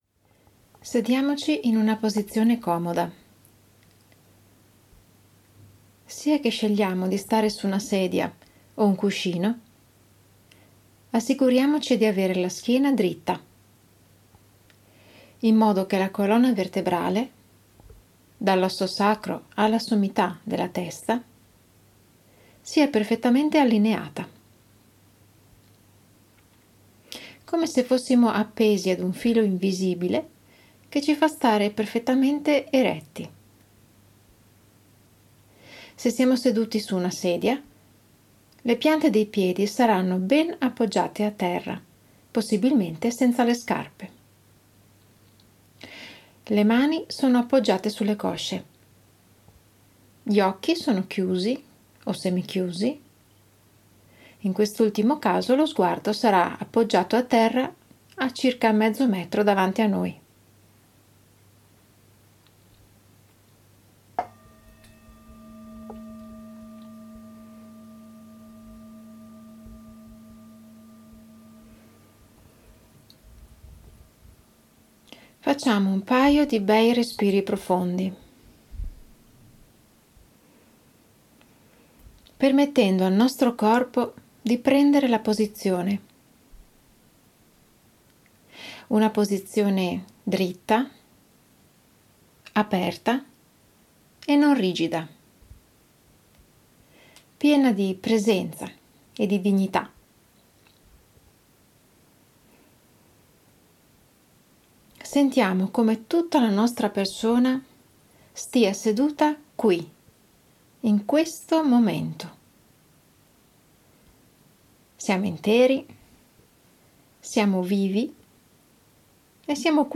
Meditazioni guidate da utilizzare in libertà per allenare la mente.
meditazione_respiro_2.mp3